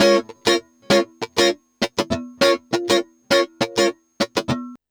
100FUNKY01-R.wav